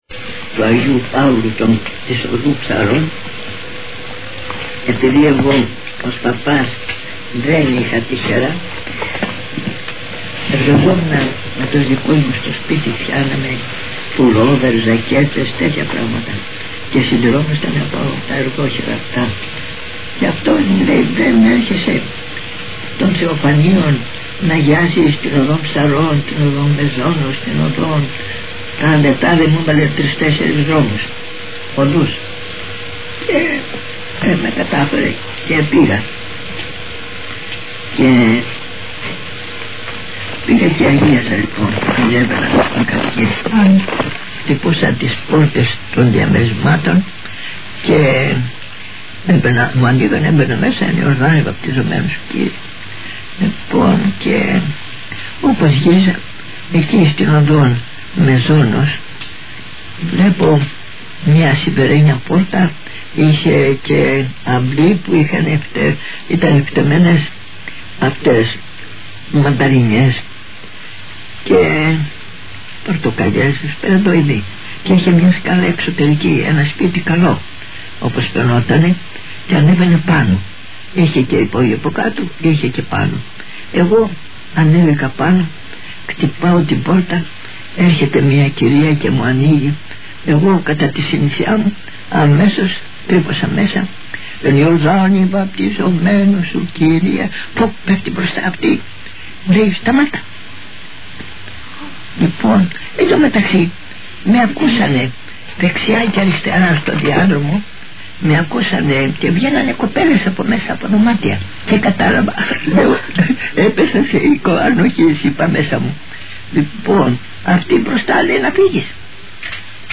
ύμνος στην Παναγία